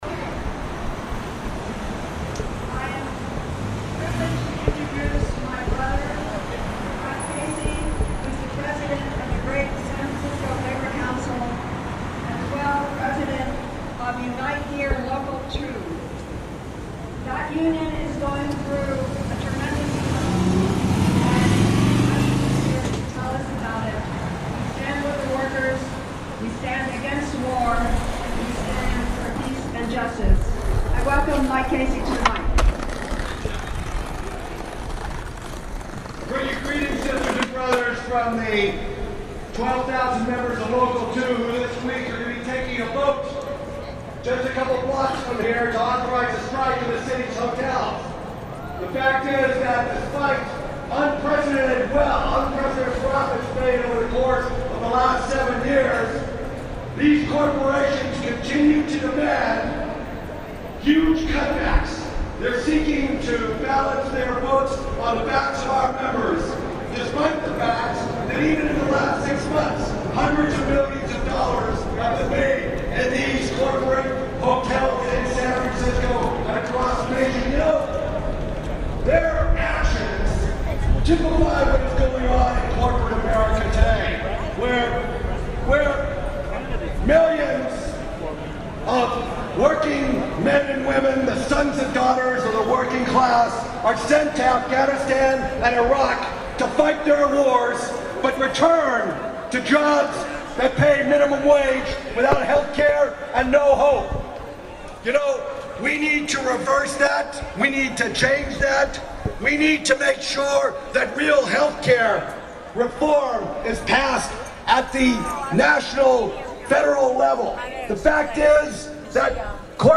Hear 45 minutes of audio from the anti-war demonstation at U.N. Plaza before and after the march on Market Street.
The action drew with a modest and spirited turnout. Speakers called for an end to the wars in Iraq, Afganistan, and Palestine, bring the troops home, and to use these resources for unmet human needs here in the U.S. Speakers bring topics including organized labor; oil industry influence on Middle East policy; the need for single payer health care; and free the Cuban 5.